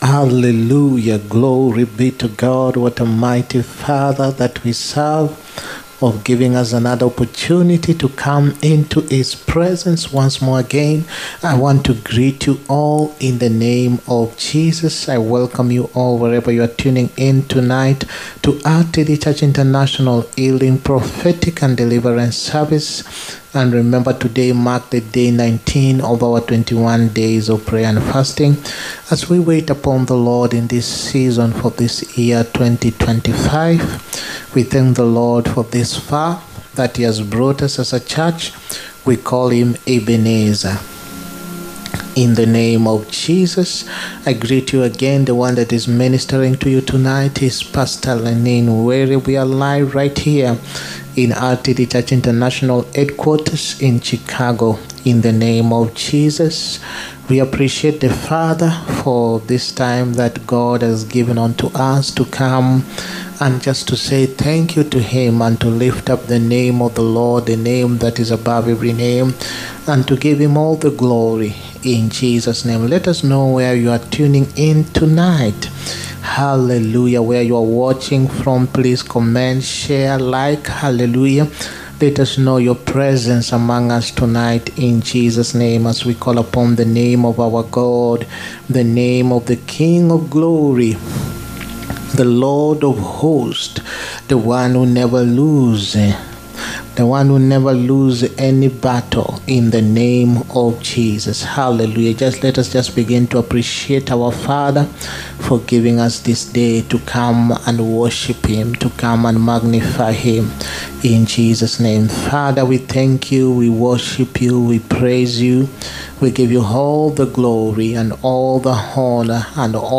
HEALING, PROPHETIC AND DELIVERANCE SERVICE. 1ST FEBRUARY 2025.